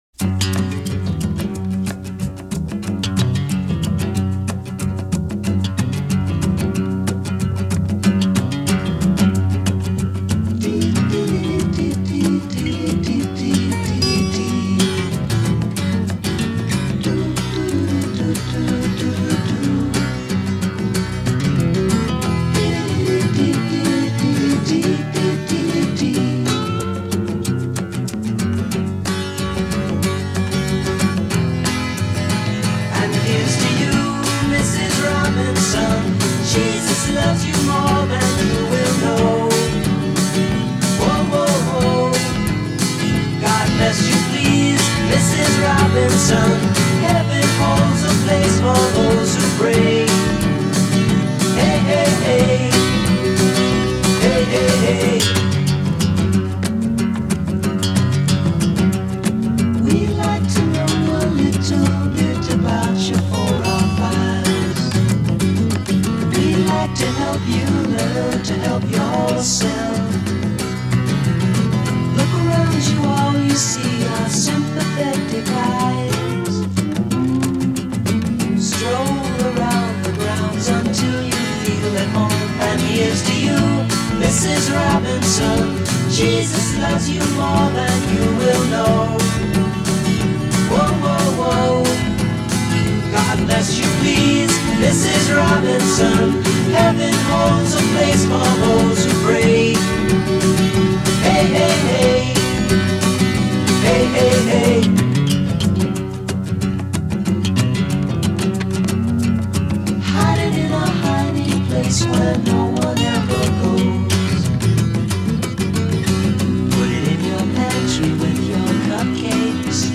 Жанр: Folk Rock, Folk, Pop